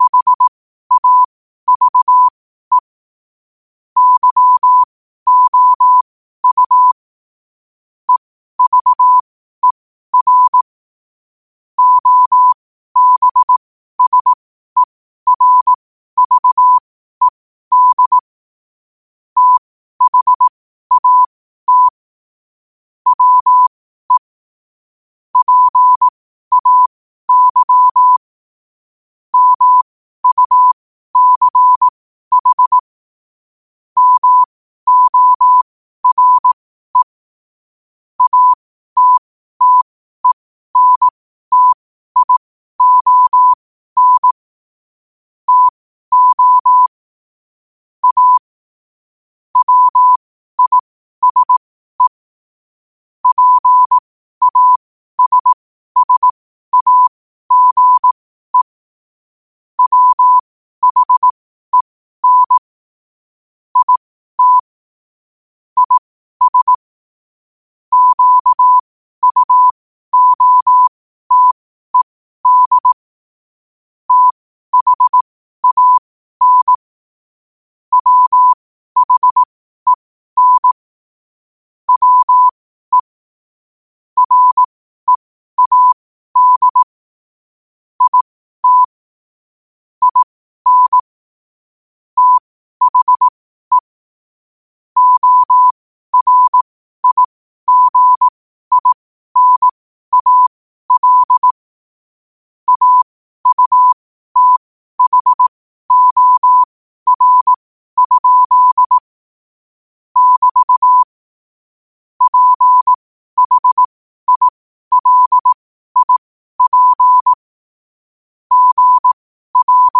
Quotes for Wed, 13 Aug 2025 in Morse Code at 12 words per minute.
Play Rate Listened List Bookmark Get this podcast via API From The Podcast Podcasts of famous quotes in morse code.